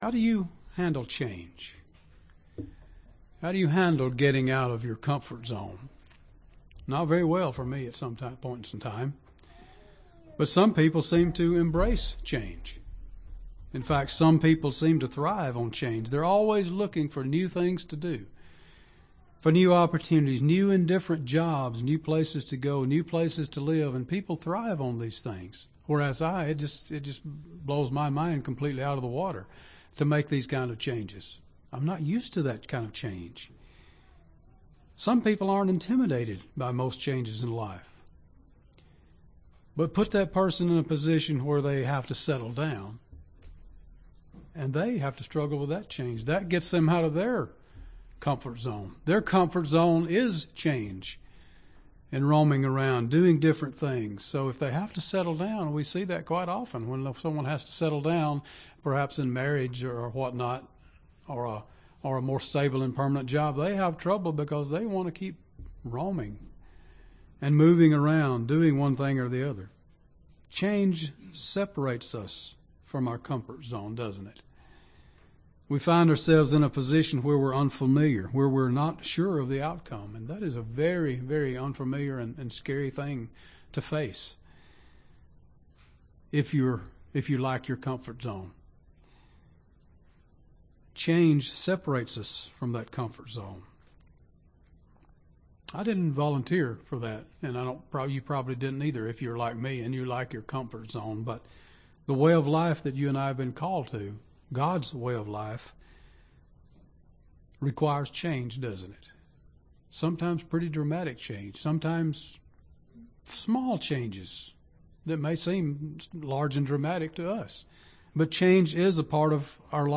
This sermon examines how God, through the power of His Holy Spirit, changes us from the inside – into a whole new creation, ready to inherit His glorious Kingdom.
Given in Gadsden, AL